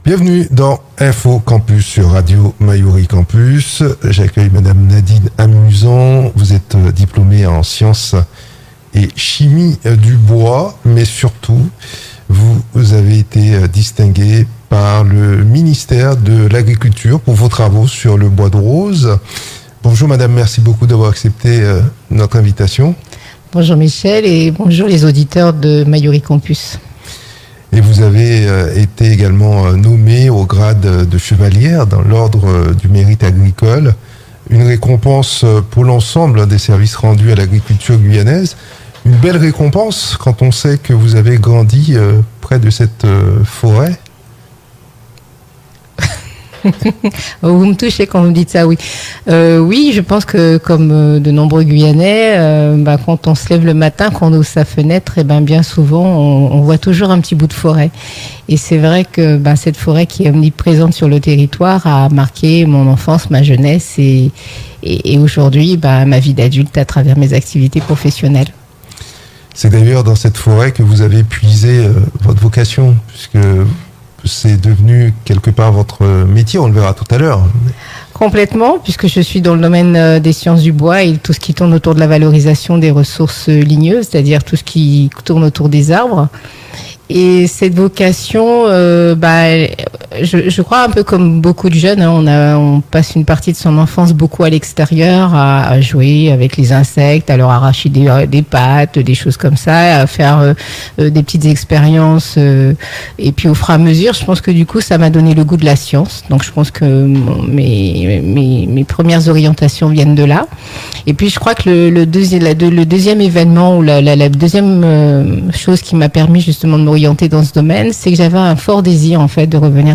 Elle était notre invité dans "Info Campus" sur Radio Mayouri Campus.